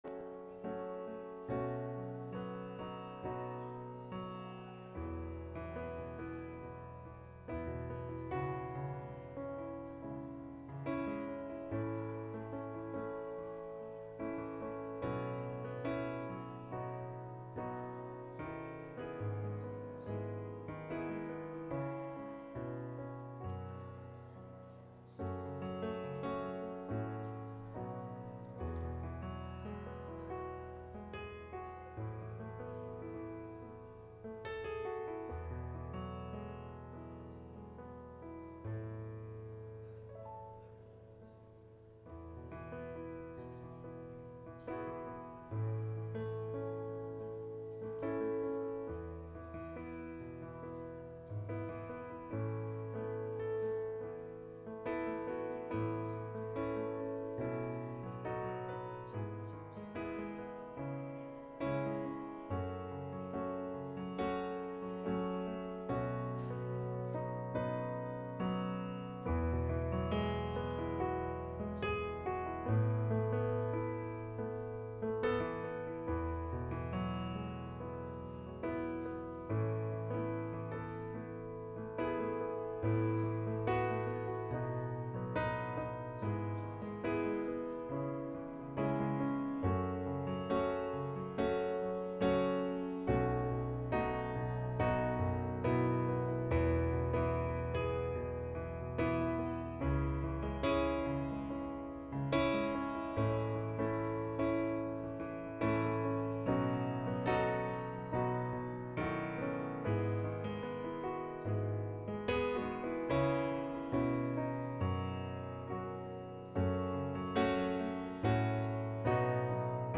2026年2月22日溫城華人宣道會粵語堂主日崇拜